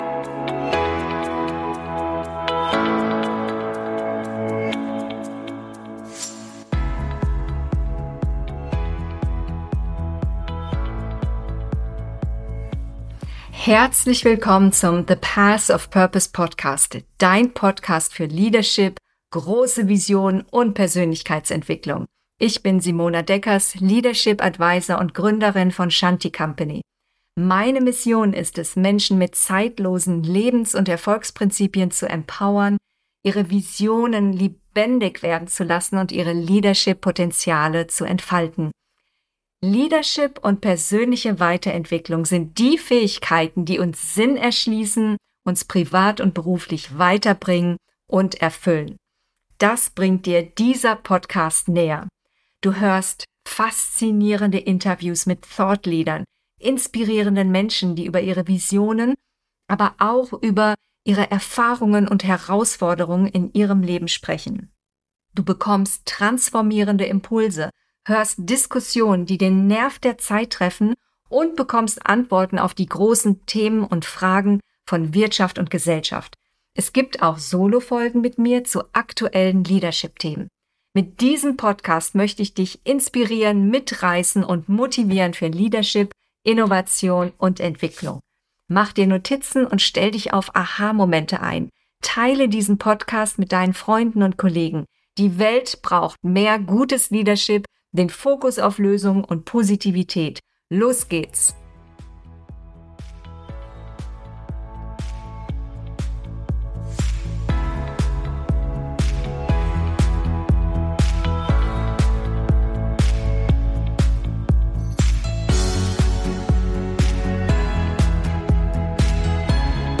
Führung und Struktur: Wenn digitaler Stress zum Dauerzustand wird - Interview